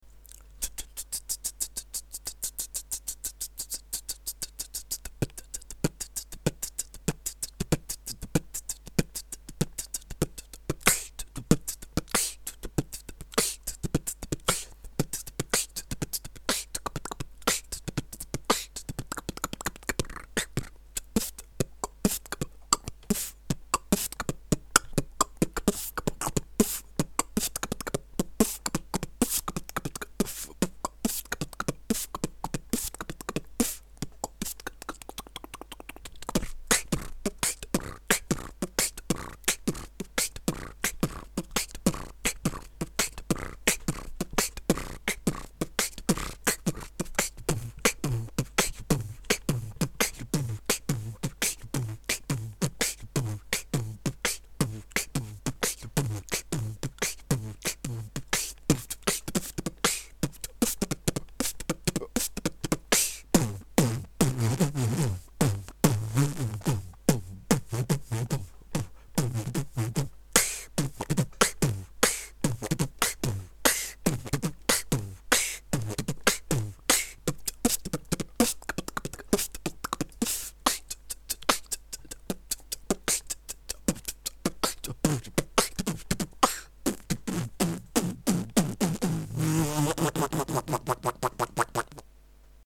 Дружеский батл
отличный хендклеп, но как то ваще не складно получилось в середине
бочка очень слабая , прям как при хаминге .